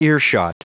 Prononciation du mot earshot en anglais (fichier audio)
Prononciation du mot : earshot